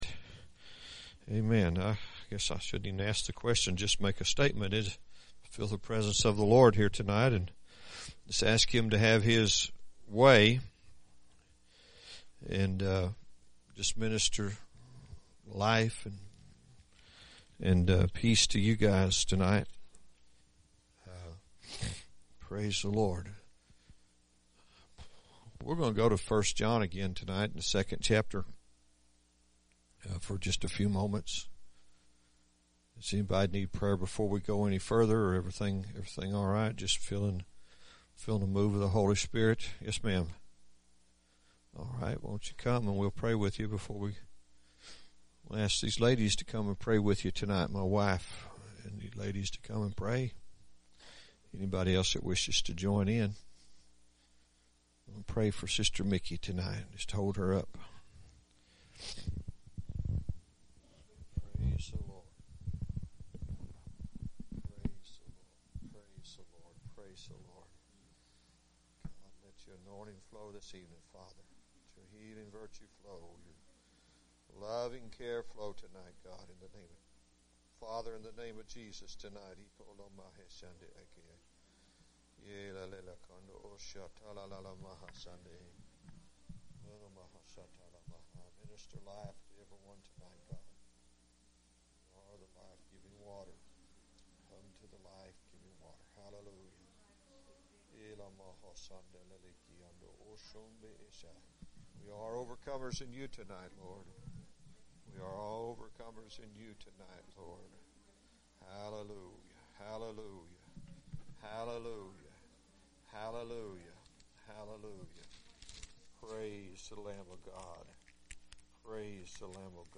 1 John Series – Sermon 3